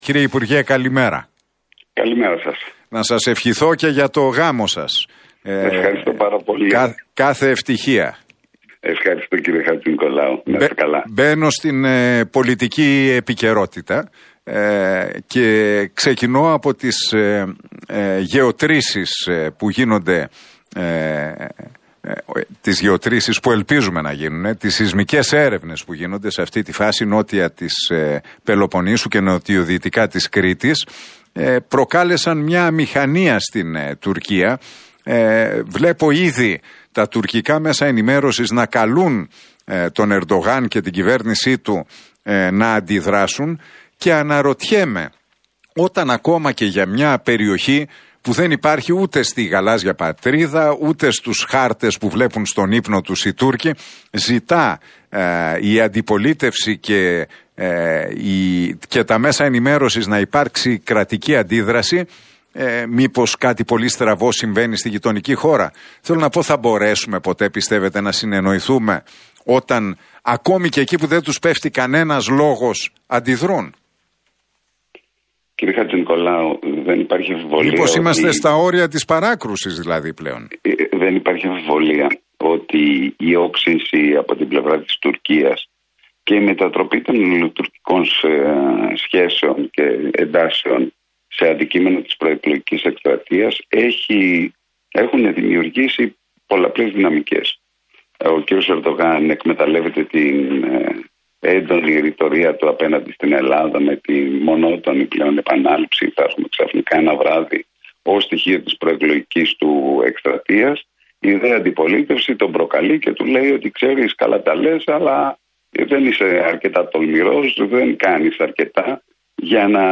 ο αναπληρωτής υπουργός Εξωτερικών Μιλτιάδης Βαρβιτσιώτης σε συνέντευξη στον Realfm 97,8